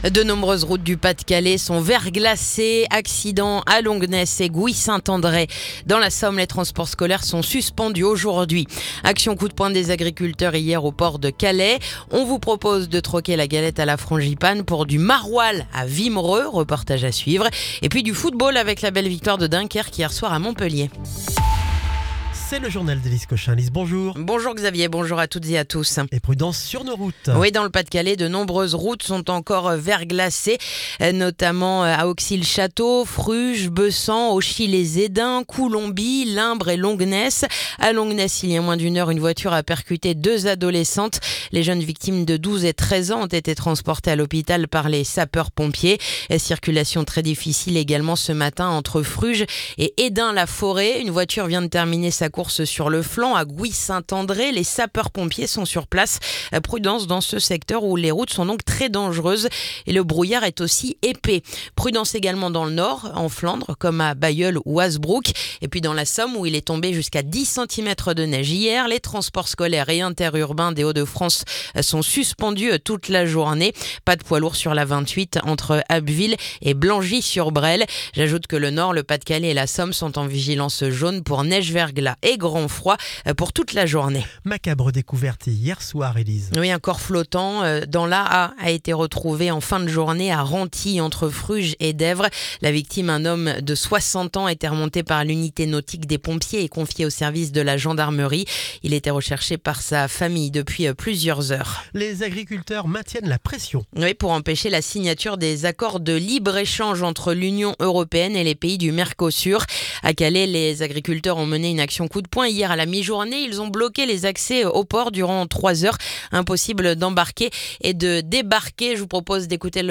Le journal du mardi 6 janvier